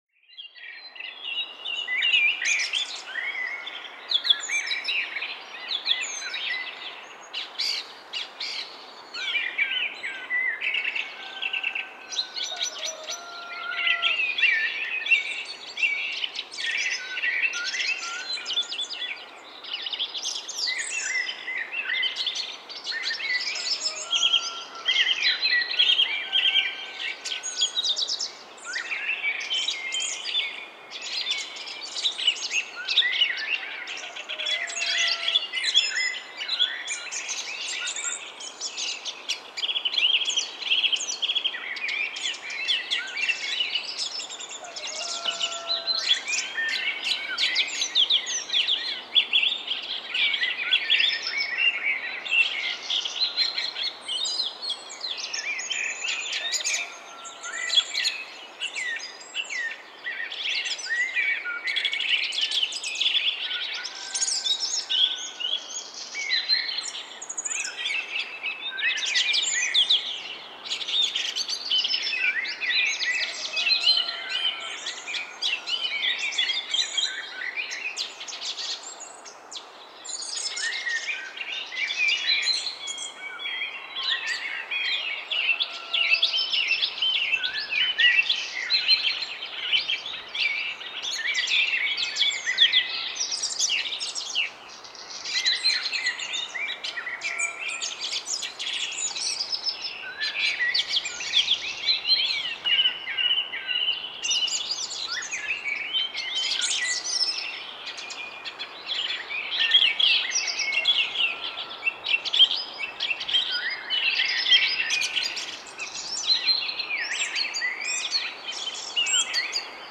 Early-morning-bird-sounds.mp3